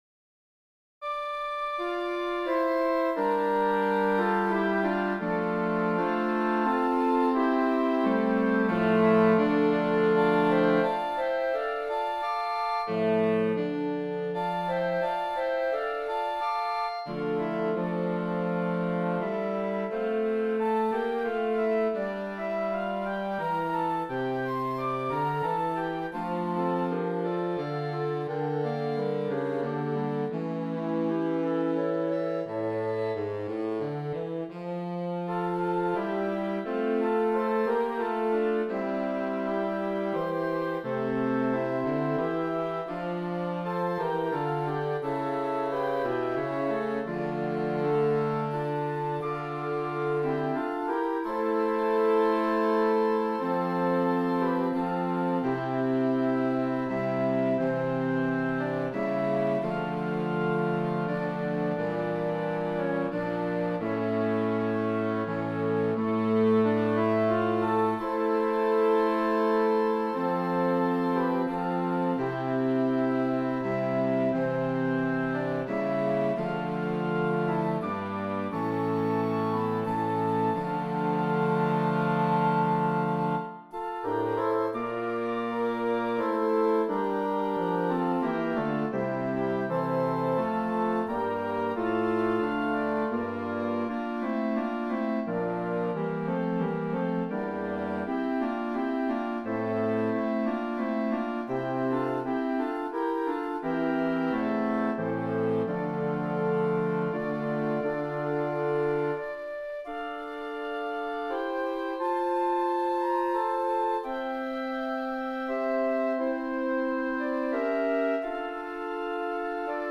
Voicing: Woodwind Ensemble